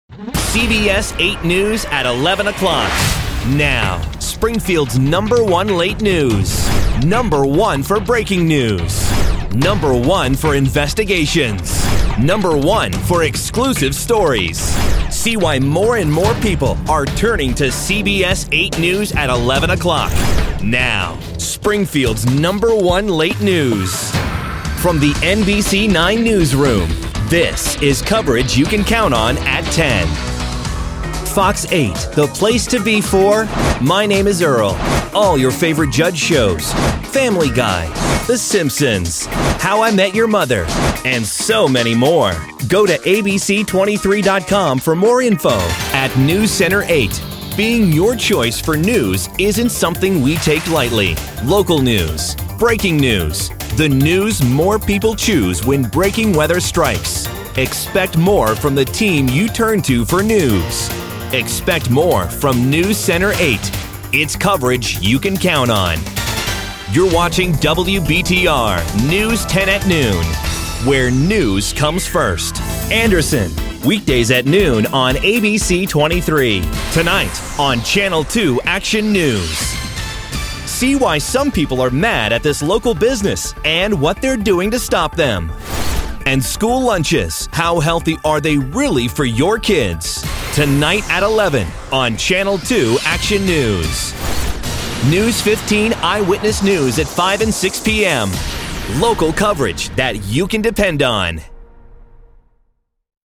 VO / TV Affiliates